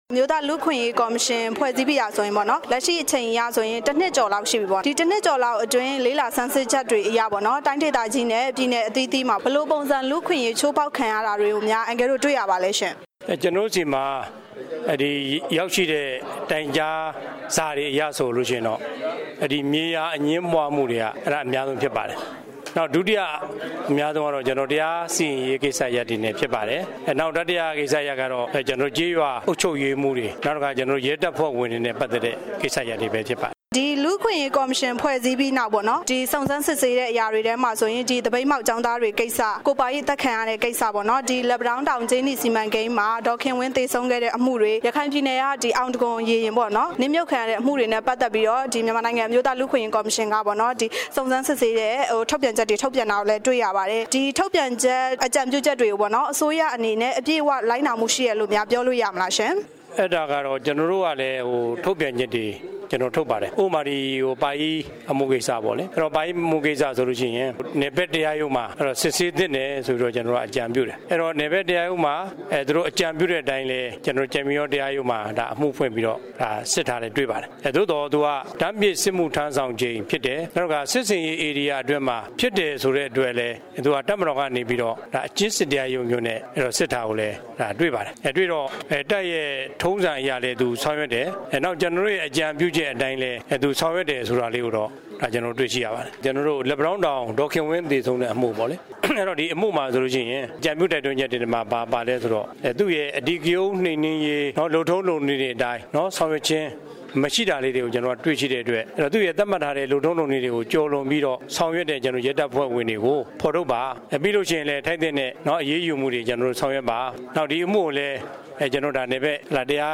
တွေ့ဆုံမေးမြန်းထားပါတယ်